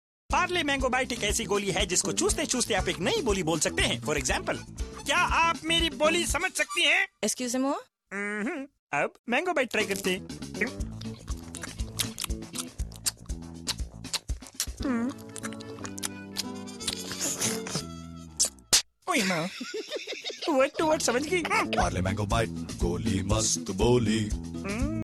File Type : Tv confectionery ads